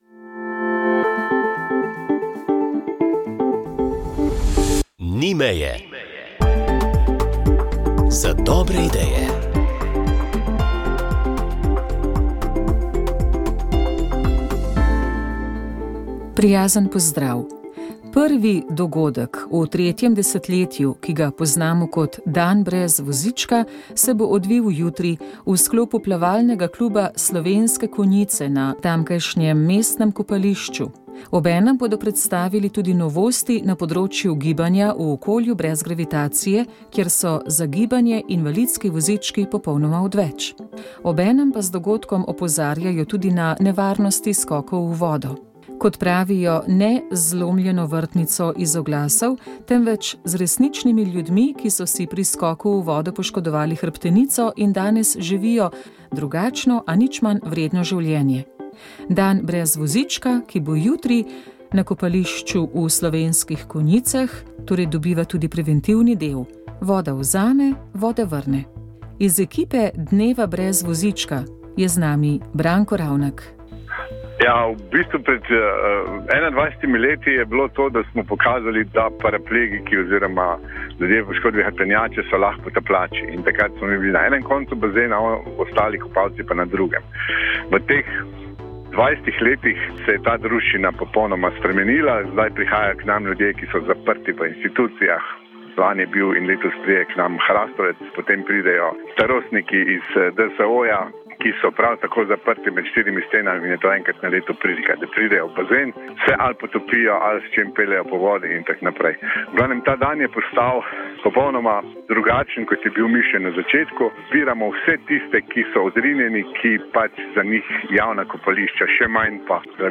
Gostja mlada pesnica